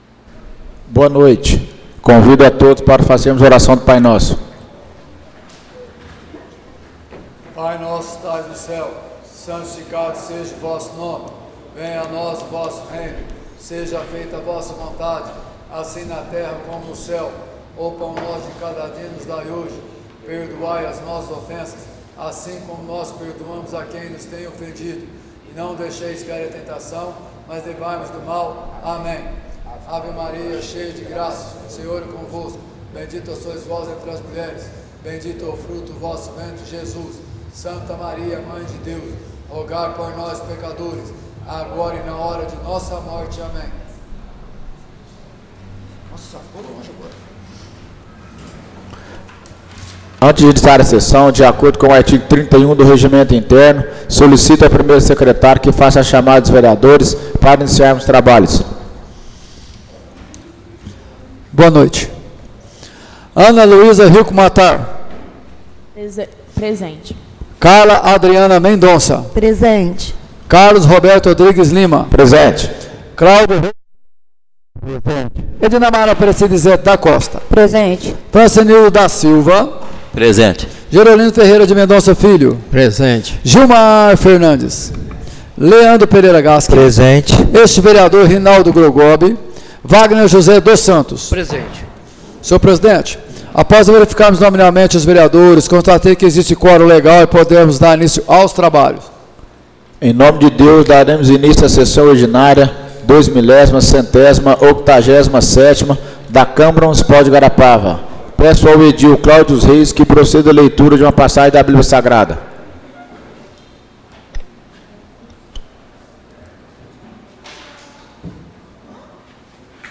Áudio da Sessão Ordinária de 14/10/2024